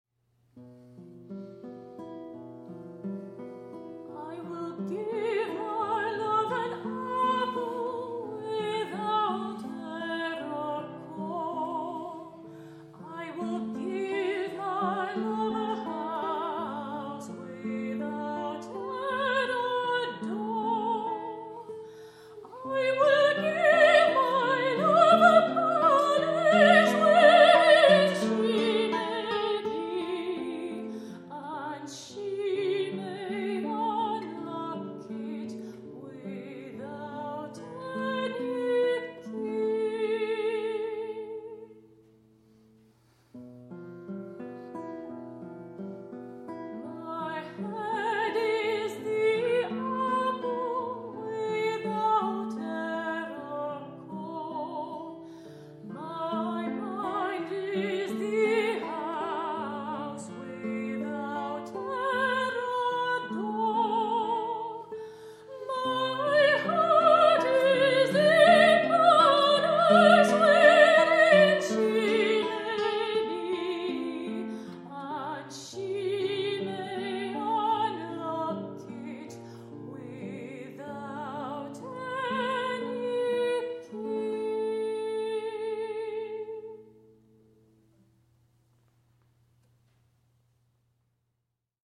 guitariste
soprano
Ils se consacrent à l’interprétations du répertoire classique et contemporain, ainsi qu’à la commande de nouvelles œuvres auprès de compositeurs et compositrices actuels, mettant en avant la complémentarité de la guitare et de la voix.